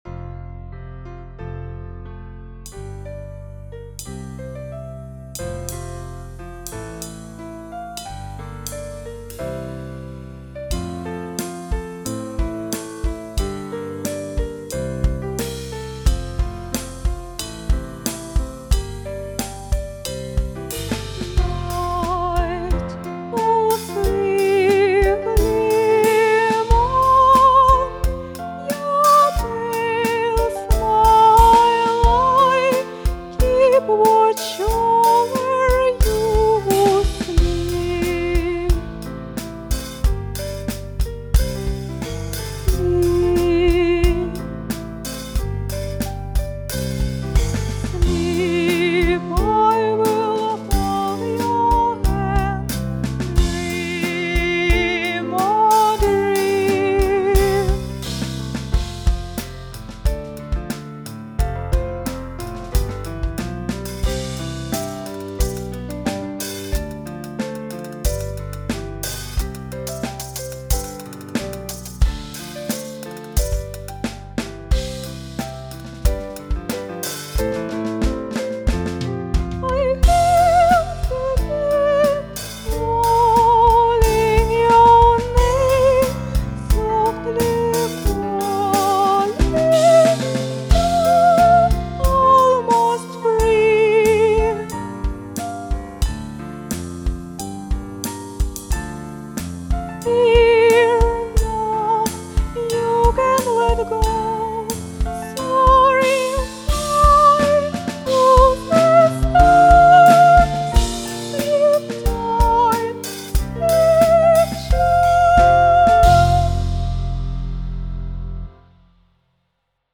• Vigil (with voice)